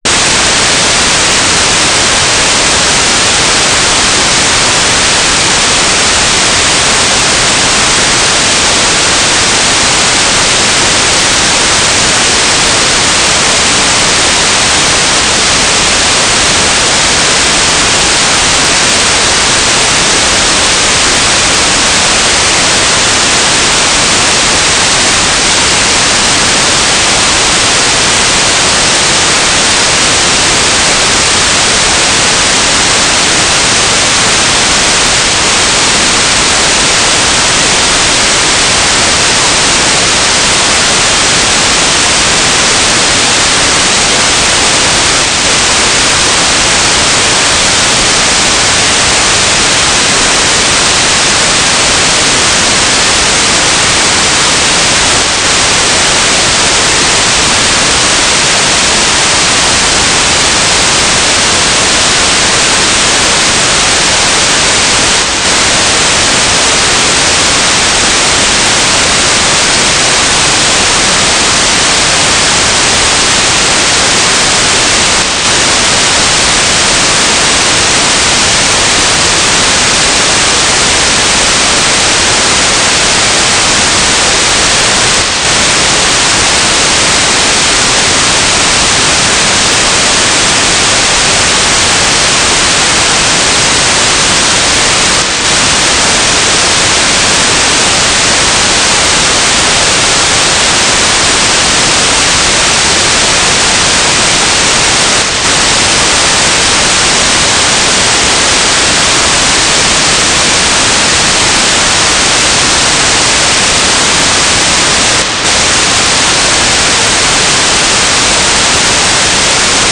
"transmitter_description": "Mode U - GMSK9k6  - AX25 G3RUH - TLM",
"transmitter_mode": "GMSK",